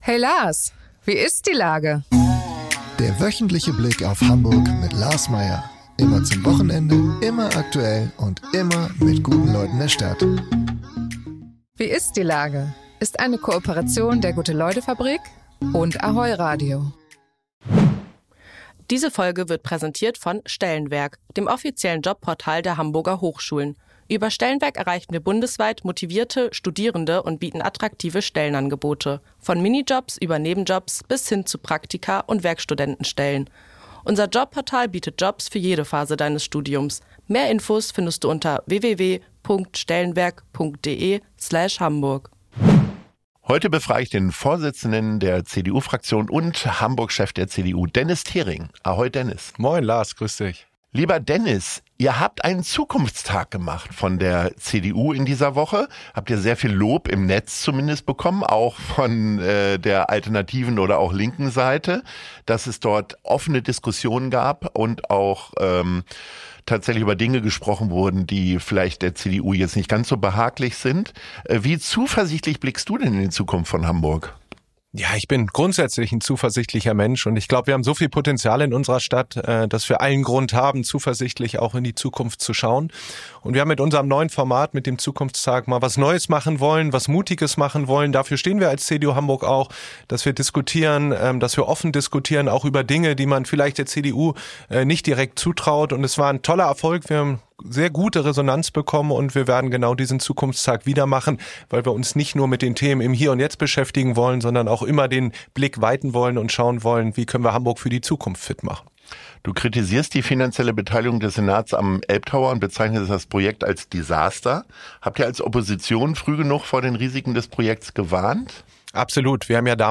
Ein Gespräch über Verantwortung, Zukunftsperspektiven und das, was Hamburg lebenswert macht.